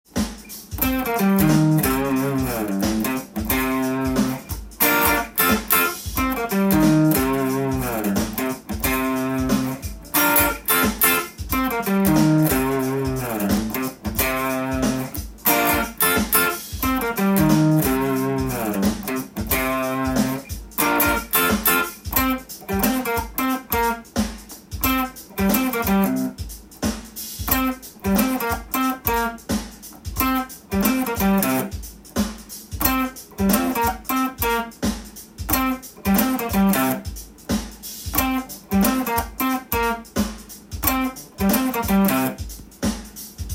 keyＣ　ギターtab譜